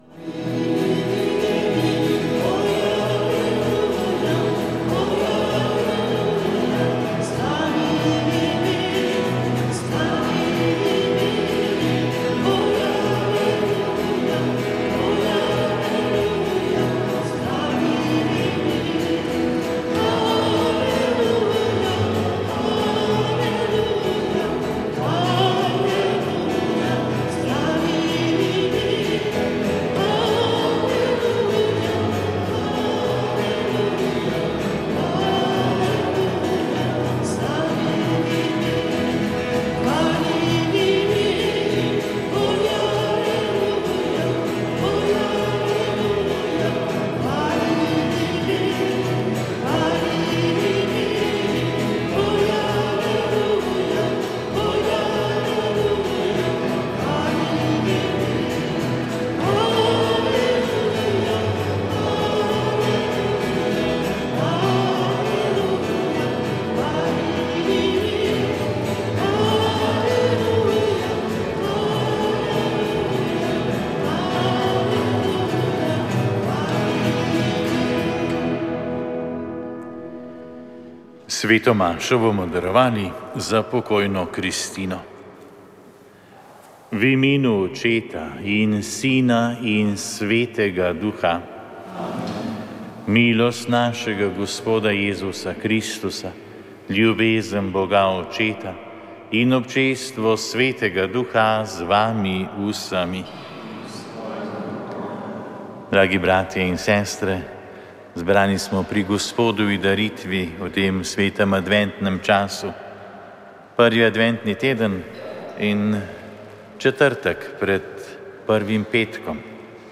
Sveta maša
Sveta maša iz župnije Štanga pri Litiji
Iz župnijske cerkve Antona Padovanskega na Štangi pri Litiji smo na 11. nedeljo med letom neposredno prenašali sveto mašo, pri kateri so sodelovali tamkajšnji verniki.